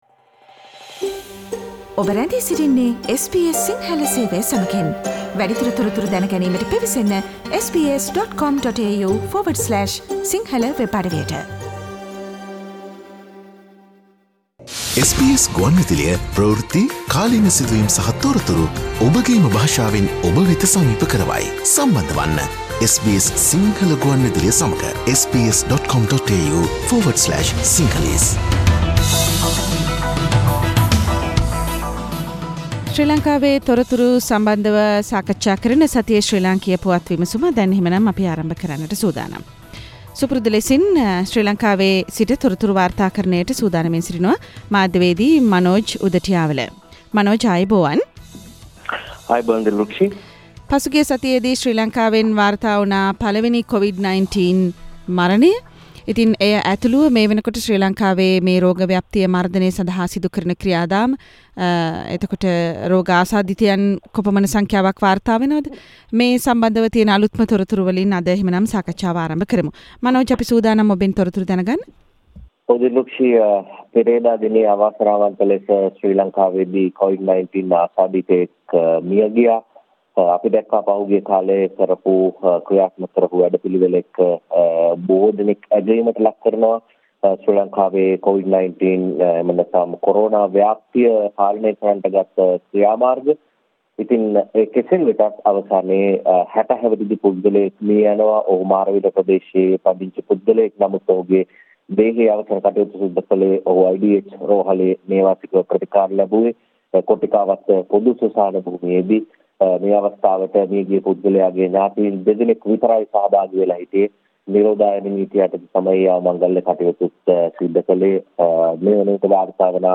SBS Sinhala radio brings you a comprehensive wrap up of the highlighted news from Sri Lanka with Journalist